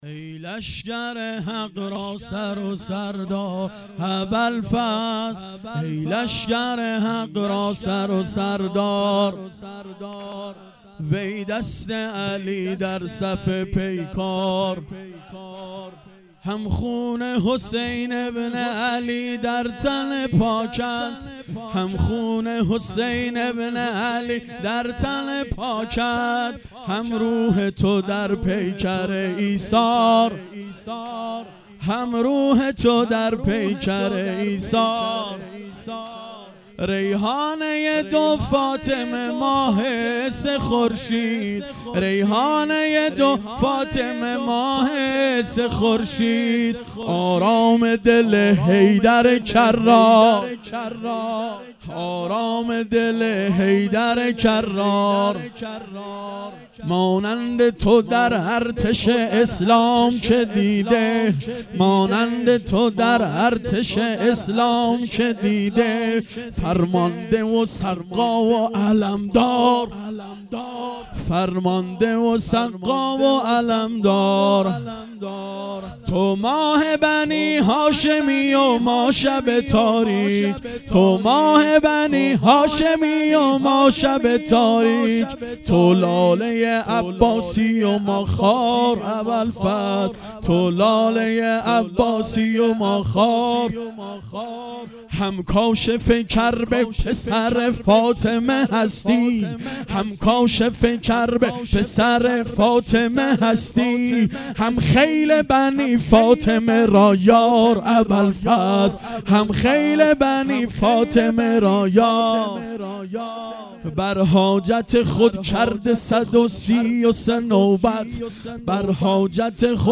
واحد تند شب نهم